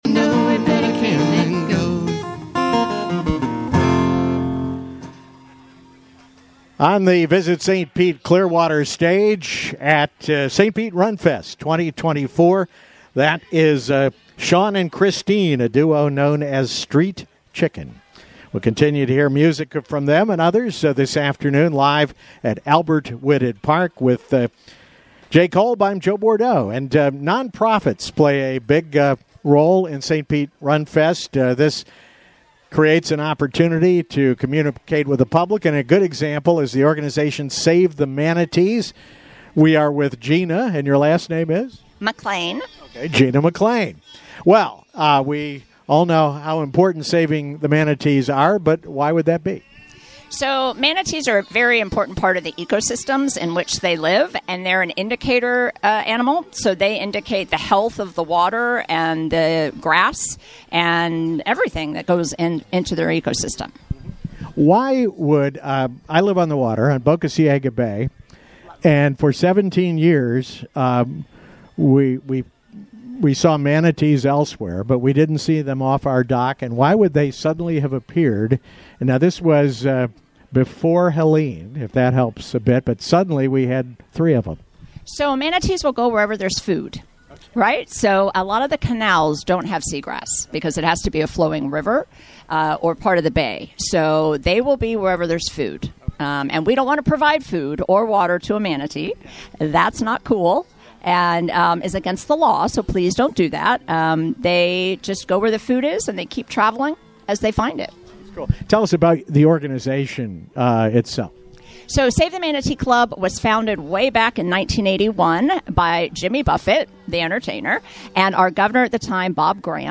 St. Pete Runfest Expo Live Coverage 11-15-24